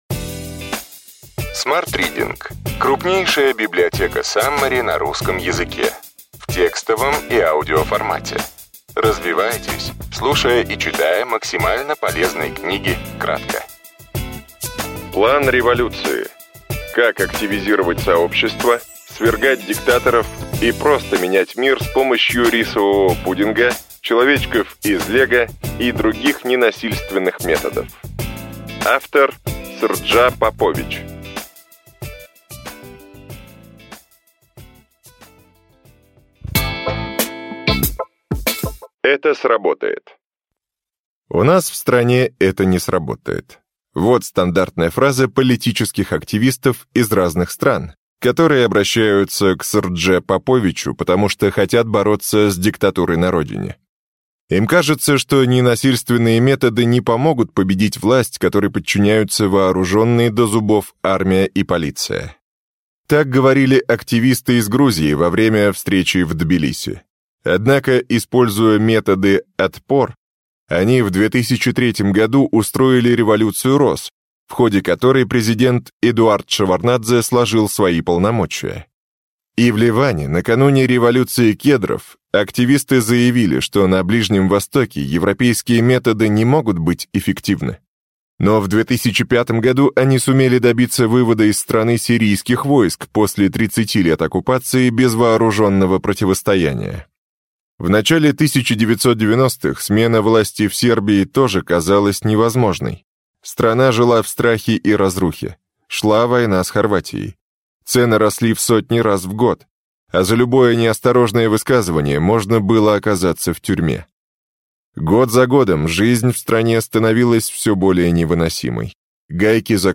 Аудиокнига Ключевые идеи книги: План революции.